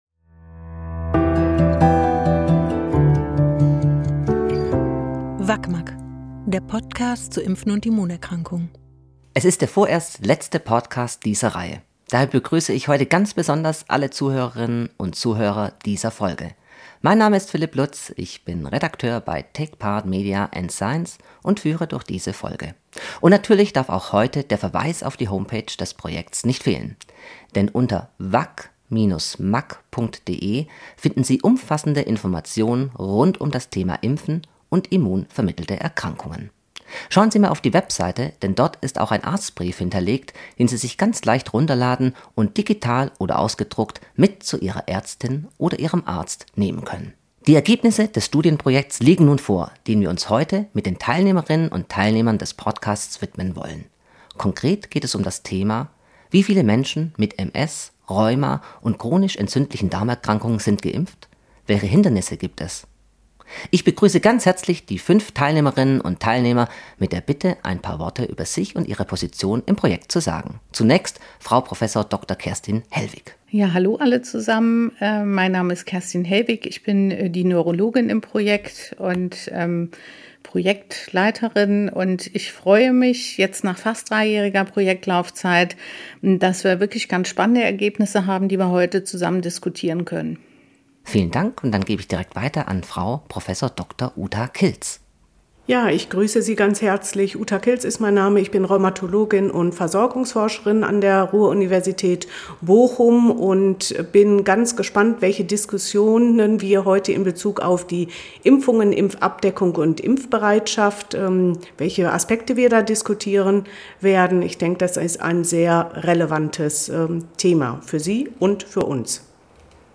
Hier greifen die Teilnehmerinnen und Teilnehmer jeweils exemplarisch interessante Ergebnisse aus allen drei Bereichen – MS, Rheuma, CED – heraus und diskutieren sie miteinander.